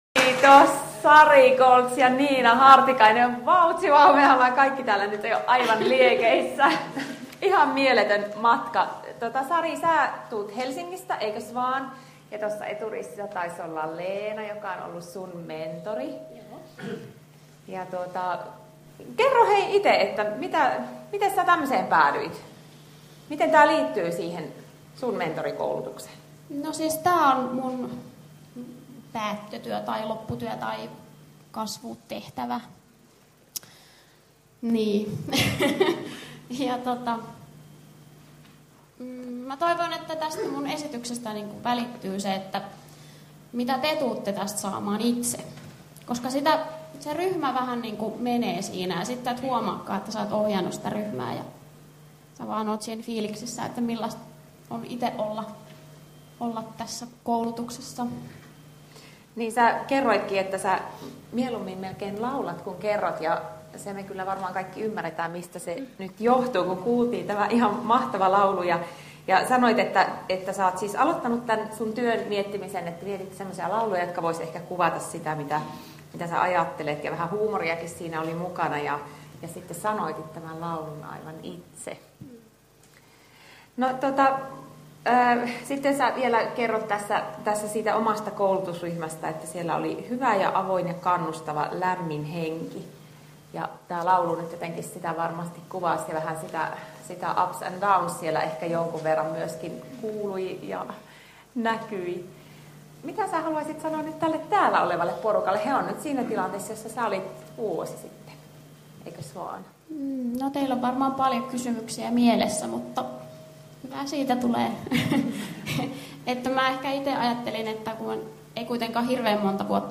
Osaava Verme - mentorikoulutuksen valtakunnallinen avausseminaari 2013 - Osa 2